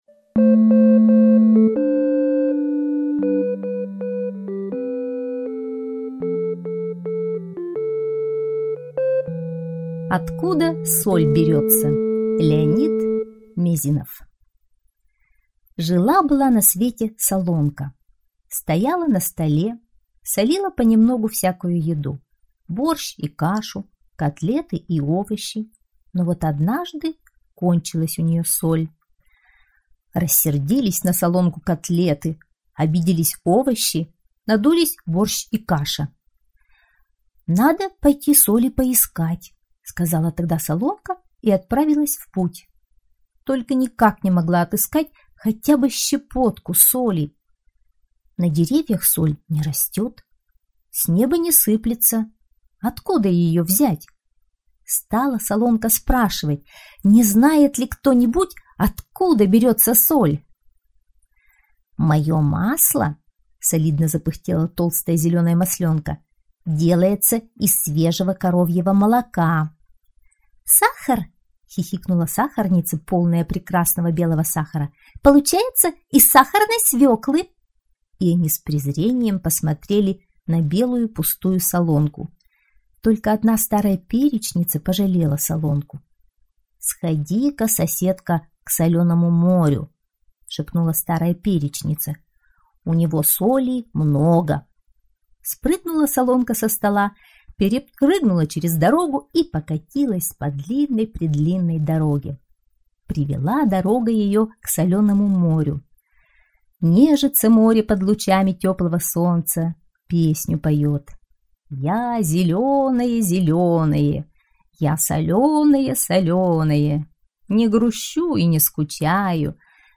Откуда соль берётся - аудиосказка Мезинова - слушать онлайн